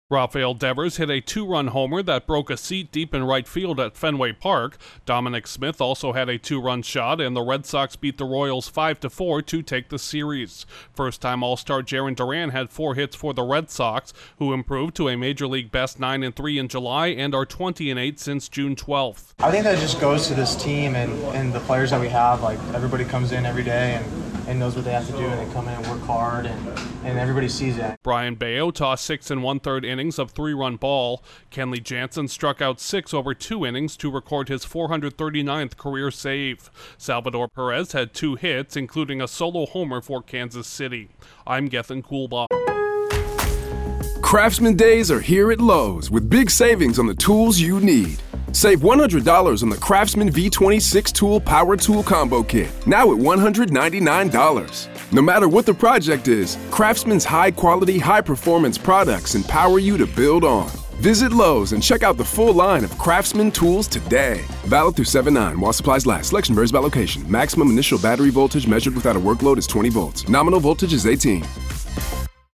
The Red Sox secured a three-game series victory over the Royals. Correspondent